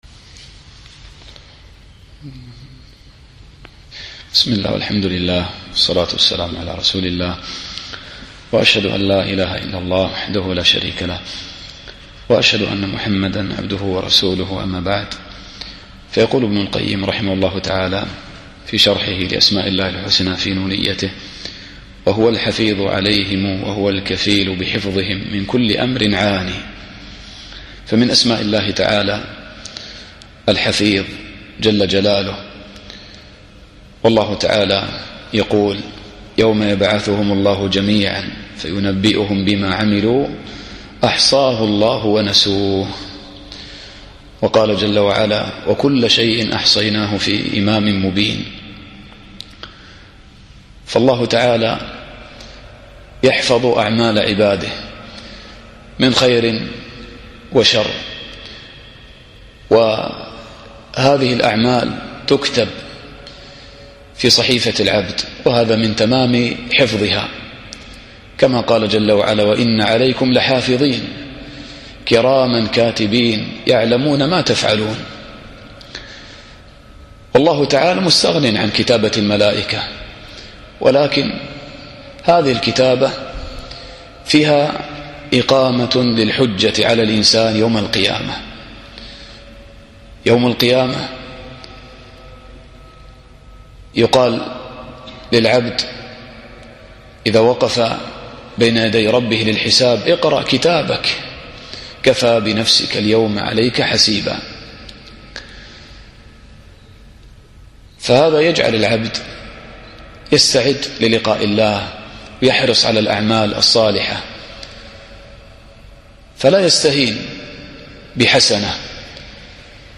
الدرس السادس والعشرون